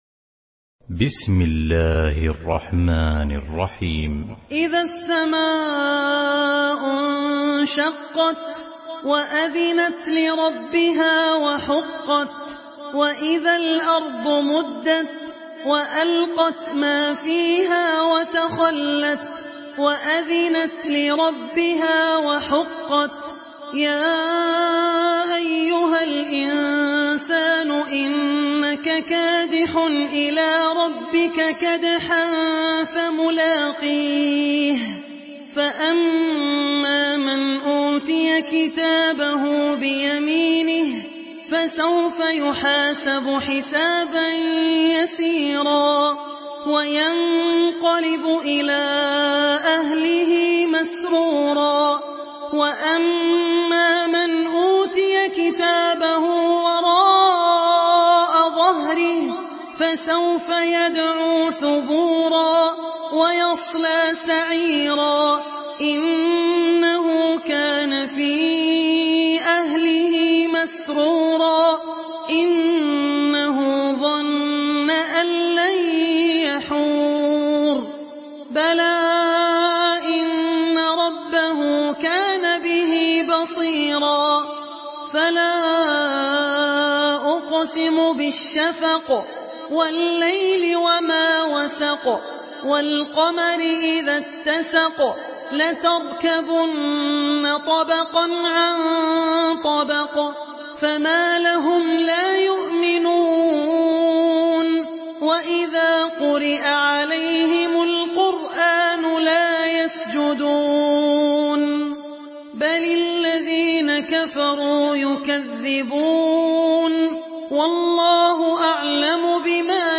Surah Al-Inshiqaq MP3 in the Voice of Abdul Rahman Al Ossi in Hafs Narration
Murattal Hafs An Asim